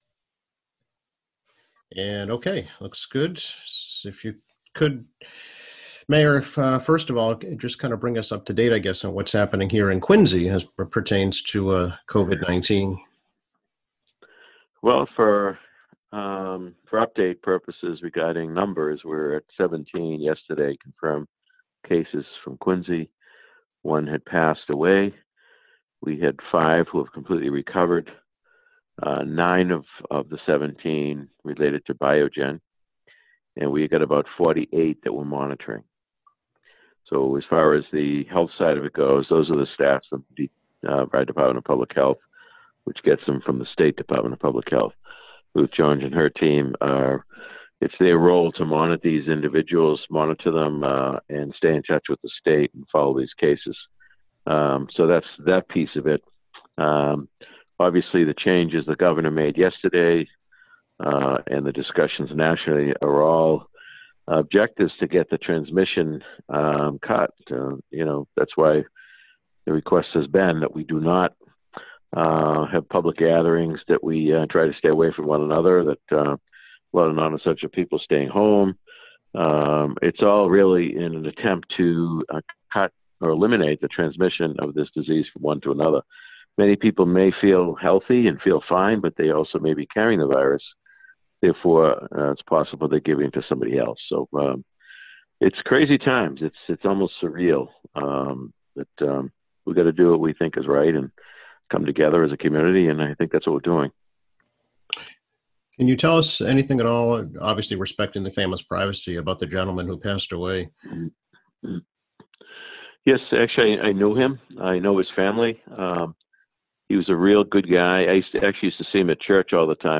Quincy Mayor Thomas Koch provides an update of the impacts of the coronavirus crisis in Quincy.